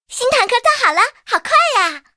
SU-26建造完成提醒语音.OGG